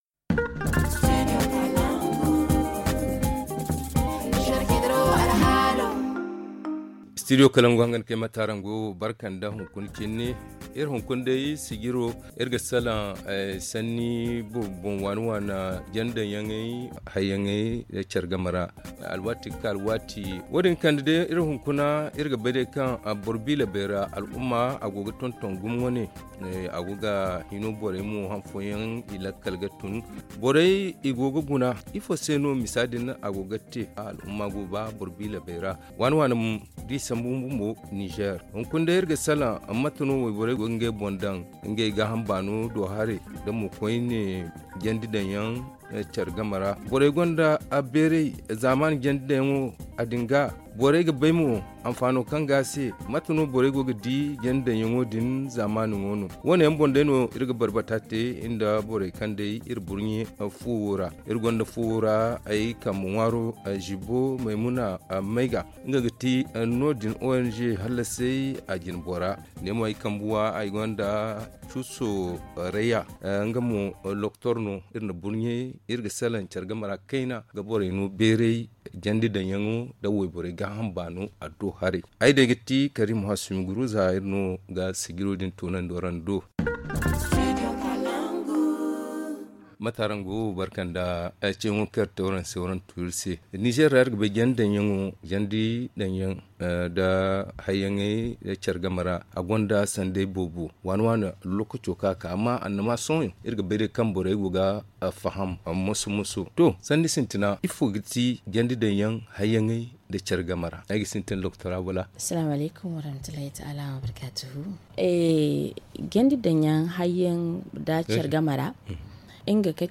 ZA Le forum en zarma https